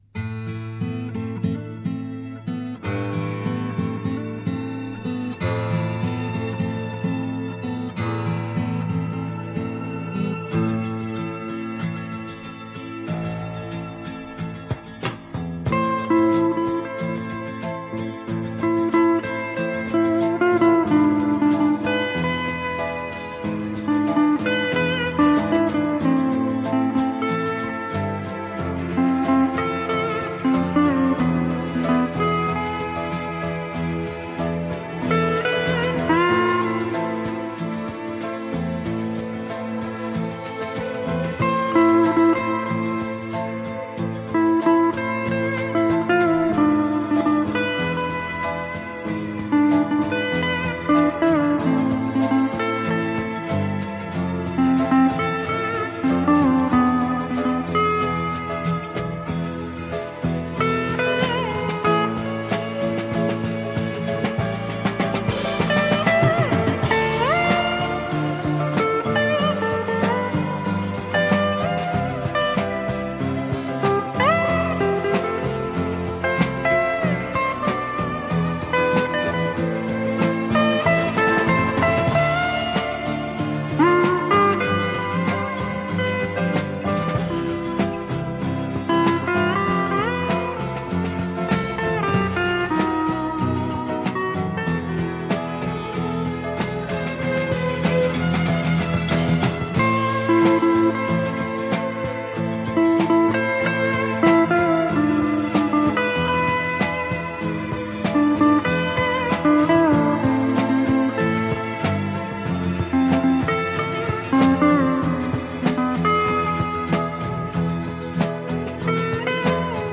radiomarelamaddalena / STRUMENTALE / GUITAR HAWAY / 1 /